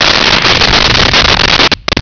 Sfx Pod Flare Fire
sfx_pod_flare_fire.wav